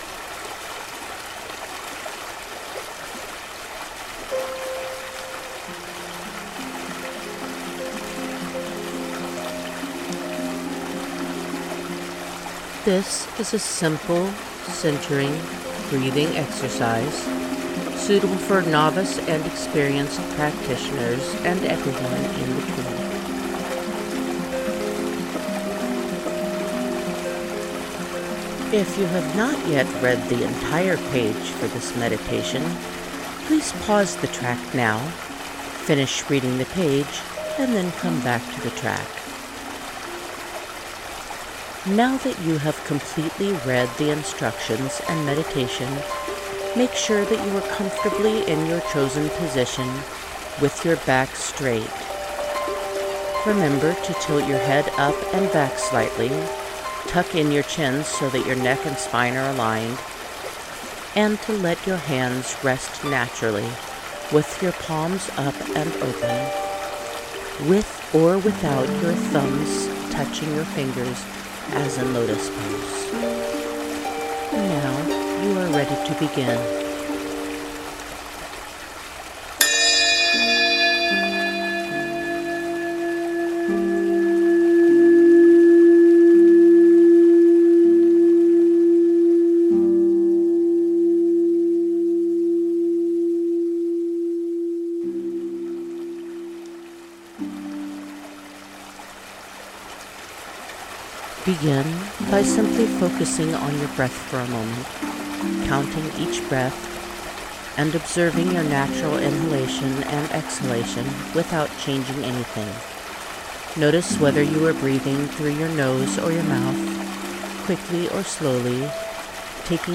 The guided meditation track uses a chime to begin this meditation, as well as most of the meditations in this series.
GuidedMeditation-centering_simple.mp3